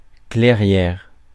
Ääntäminen
Ääntäminen France: IPA: /klɛ.ʁjɛʁ/ Haettu sana löytyi näillä lähdekielillä: ranska Käännös Substantiivit 1. поляна {f} (poljána) Muut/tuntemattomat 2. сечище {n} 3. просека {f} Suku: f .